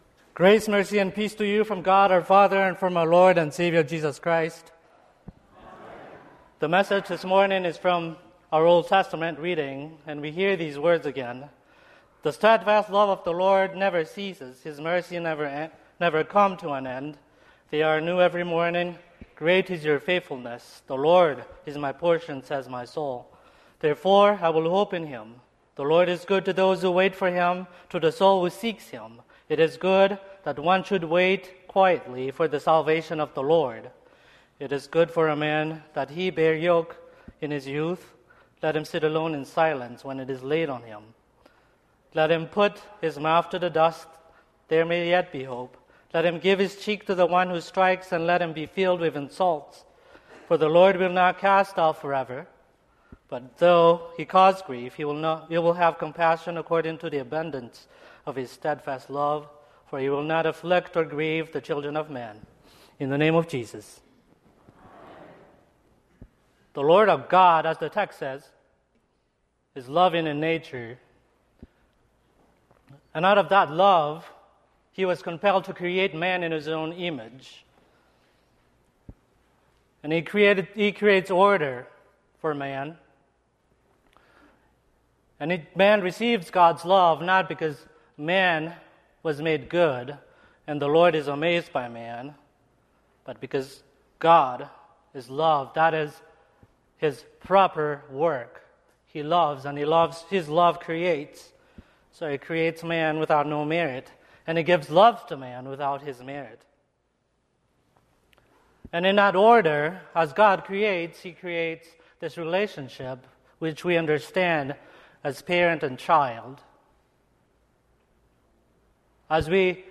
Sermon - 6/30/2024 - Wheat Ridge Lutheran Church, Wheat Ridge, Colorado